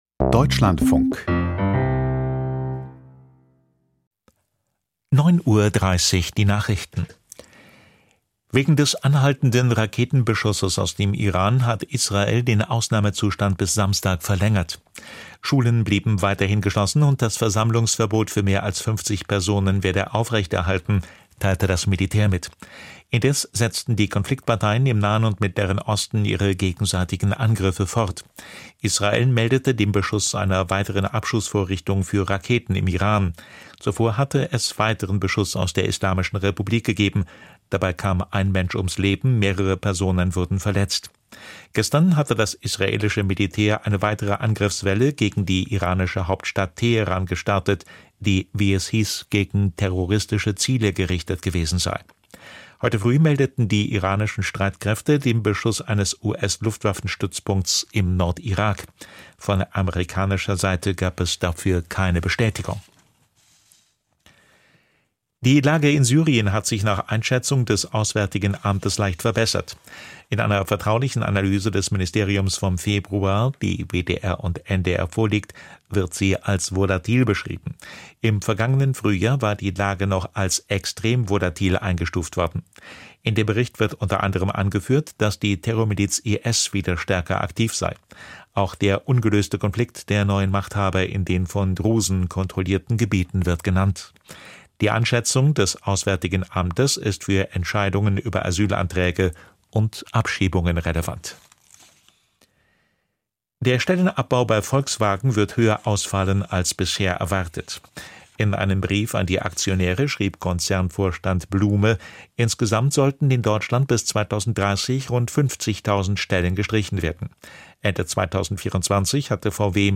Die Nachrichten vom 10.03.2026, 09:30 Uhr
Aus der Deutschlandfunk-Nachrichtenredaktion.